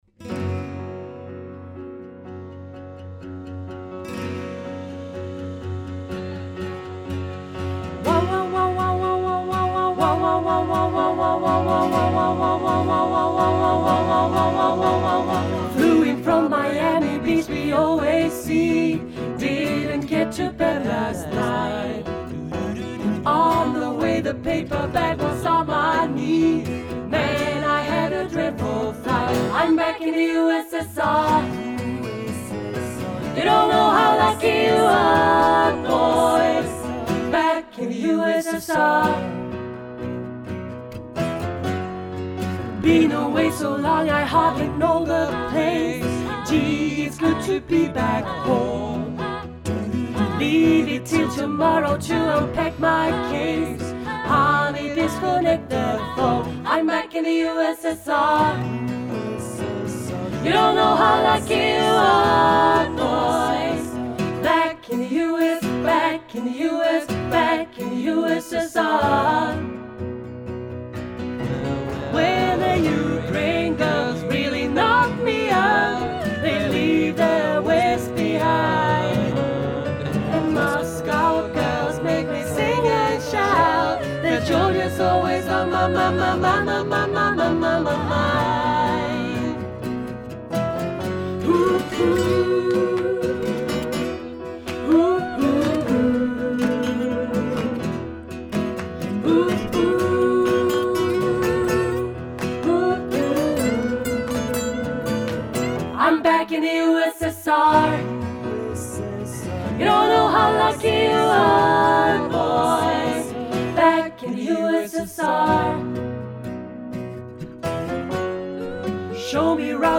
för 4-stämmig blandad kör